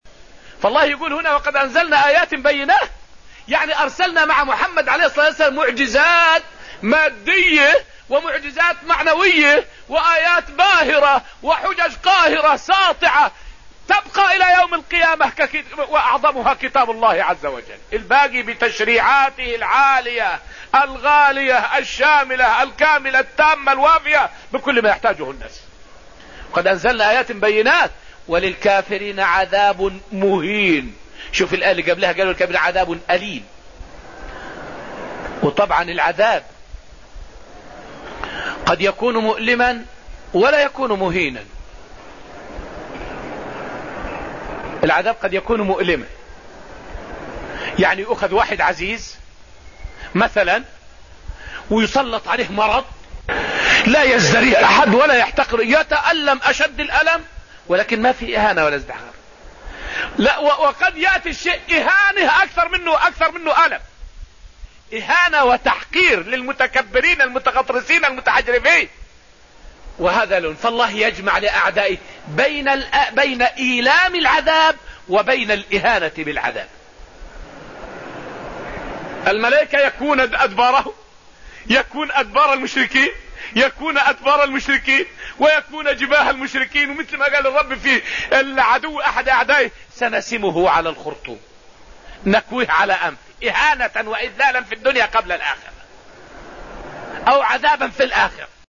فائدة من الدرس الرابع من دروس تفسير سورة المجادلة والتي ألقيت في المسجد النبوي الشريف حول الفرق بين العذاب الأليم والمهين.